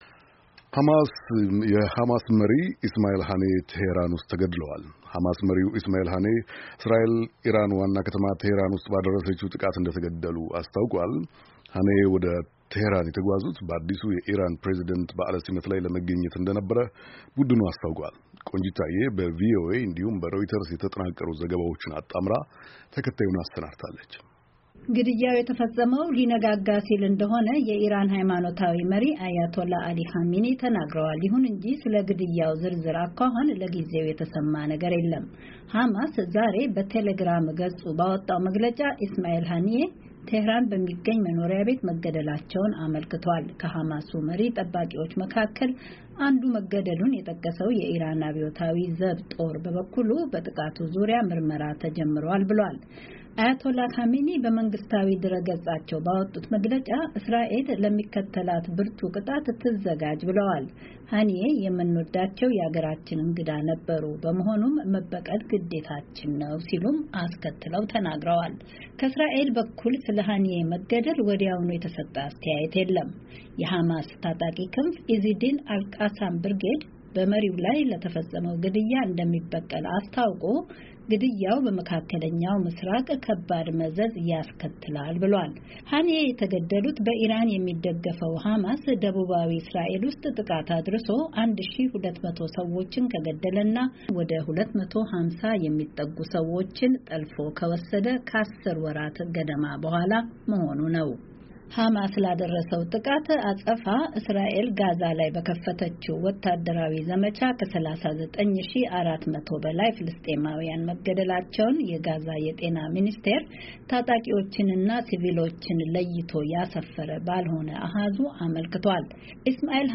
ቀጣዩ ዘገባ፣ በአሜሪካ ድምፅ እና በሌሎች የዜና አውታሮች የተጠናቀሩ ዜናዎችን አካቷል፡፡